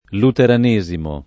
luteranesimo [ luteran %@ imo ]